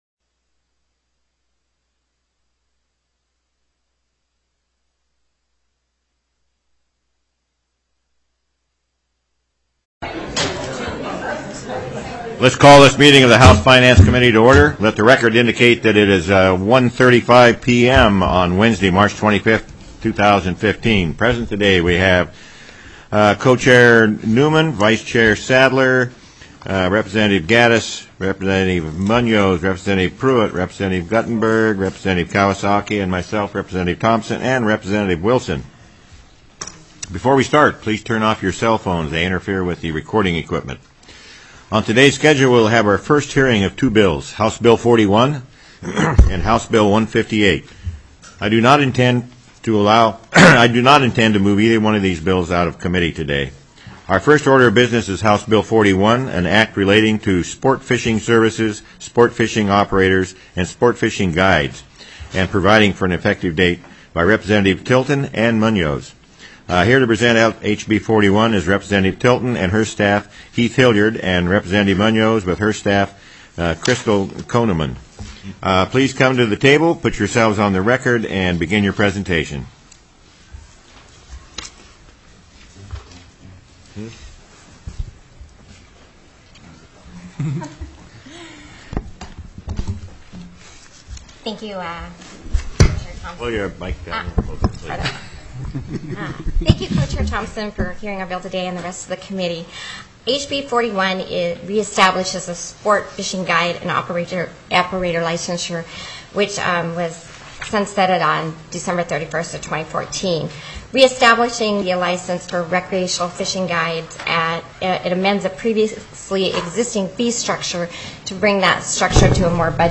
The audio recordings are captured by our records offices as the official record of the meeting and will have more accurate timestamps.
HB 41 SPORT FISHING SERVICES TELECONFERENCED